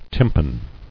[tym·pan]